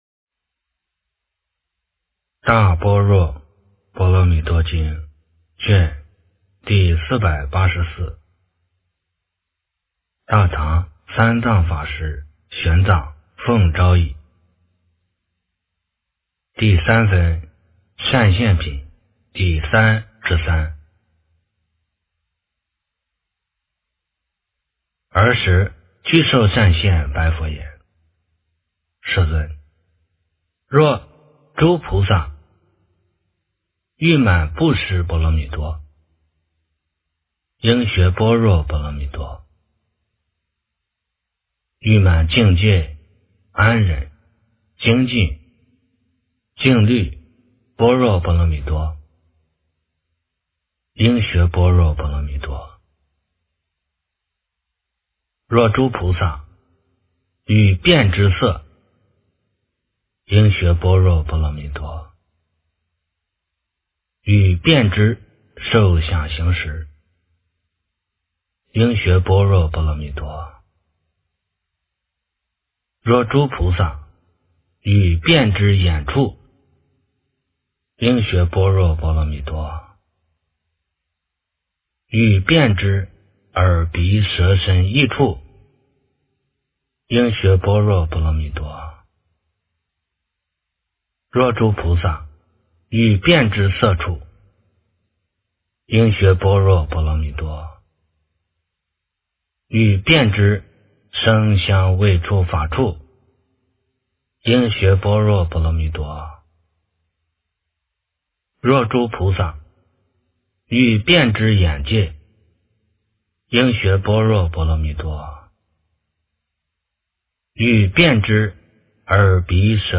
大般若波罗蜜多经第484卷 - 诵经 - 云佛论坛